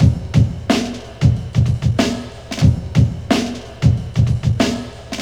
• 92 Bpm Drum Loop A# Key.wav
Free breakbeat sample - kick tuned to the A# note. Loudest frequency: 847Hz
92-bpm-drum-loop-a-sharp-key-CQM.wav